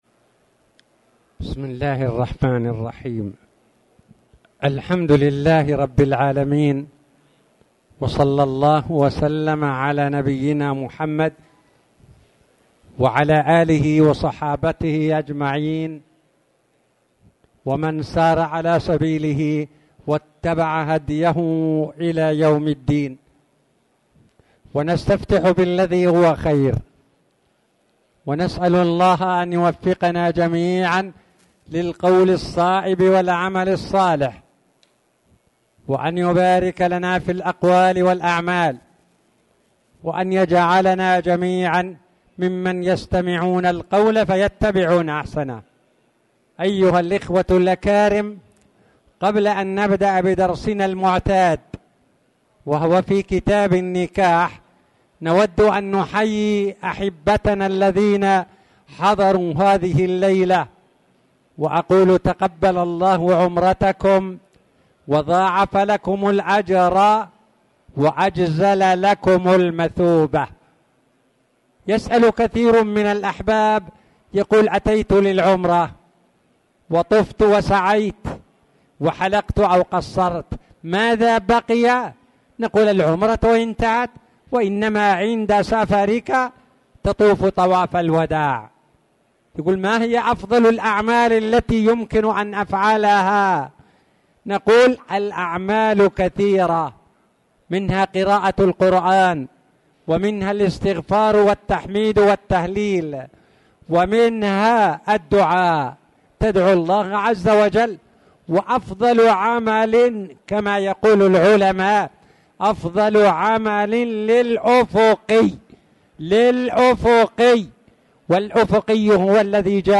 تاريخ النشر ١٥ صفر ١٤٣٨ هـ المكان: المسجد الحرام الشيخ